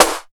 95 SNARE 2.wav